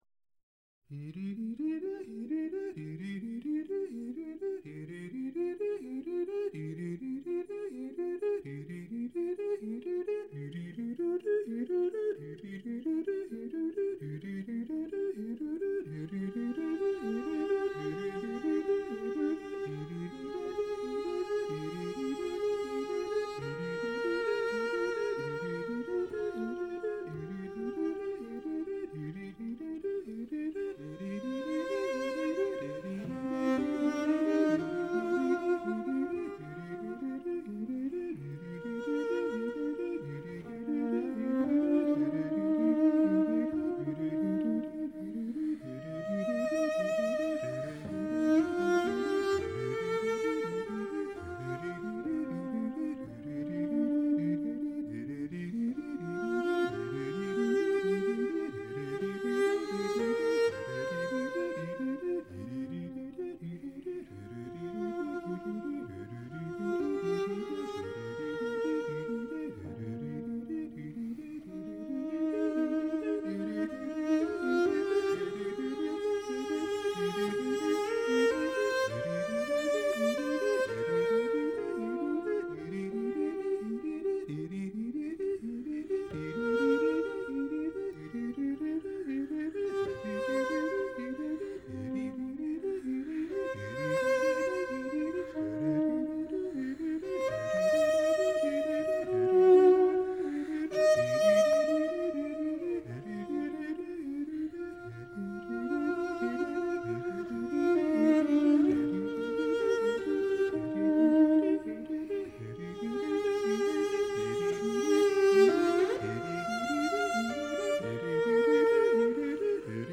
виолончель
вокал